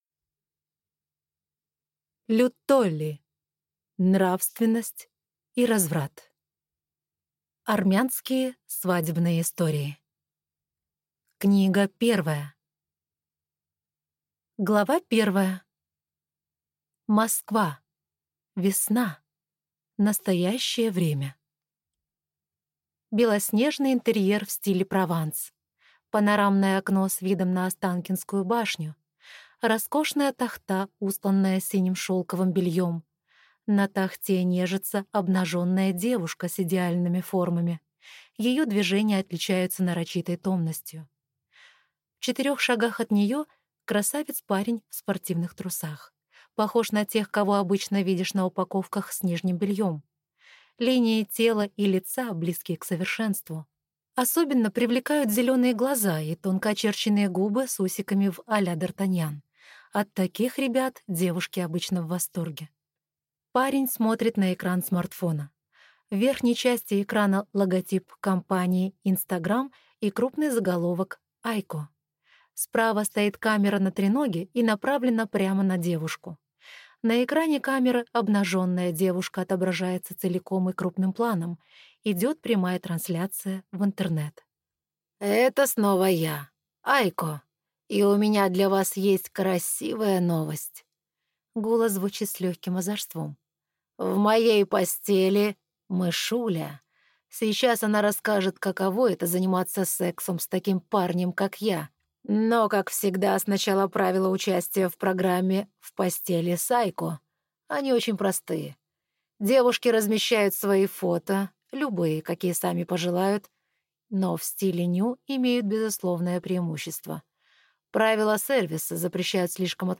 Аудиокнига Нравственность и разврат | Библиотека аудиокниг